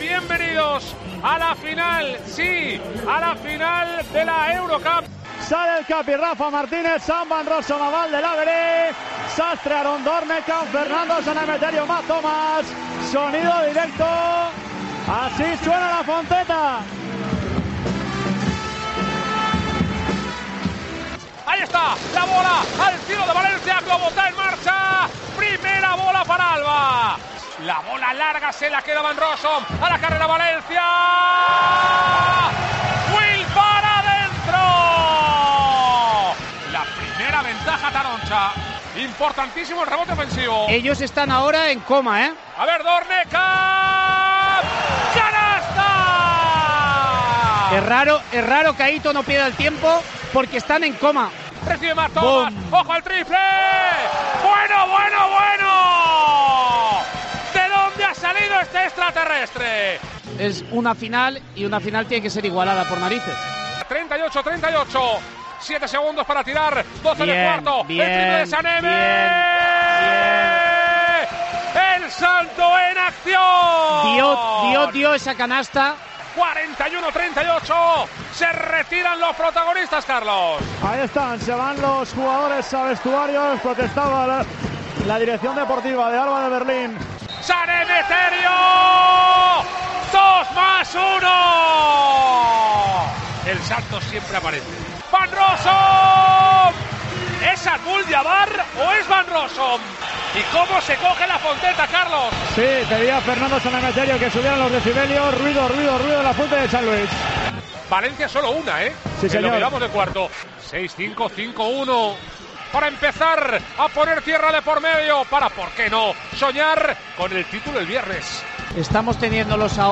Así sonó la Final de la Eurocup en Tiempo de Juego